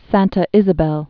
(săntə ĭzə-bĕl, sänē-sä-bĕl)